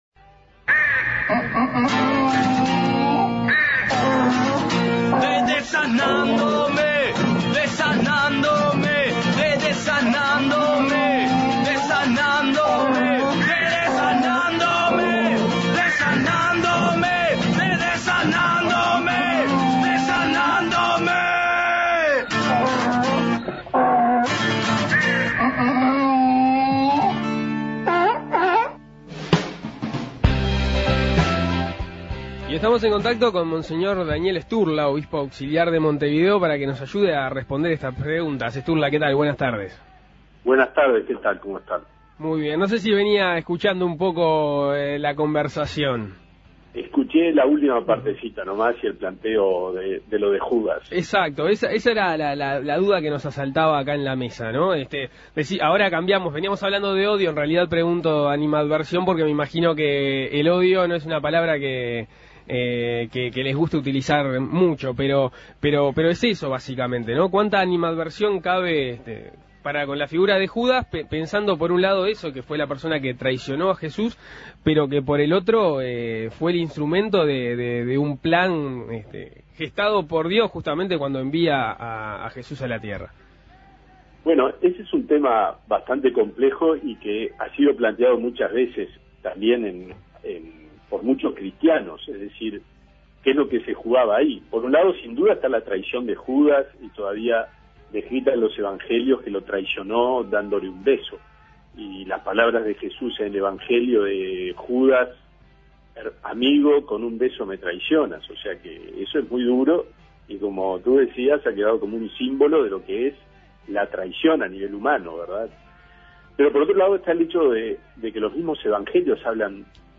Contacto telefónico con Monseñor Daniel Sturla, Obispo auxiliar de Montevideo.